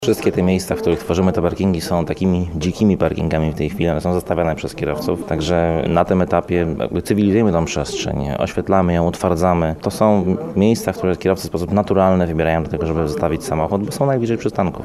Jak podkreśla burmistrz Radzymina Krzysztof Chaciński, to sami mieszkańcy wskazują miejsca, gdzie powinny powstać parkingi.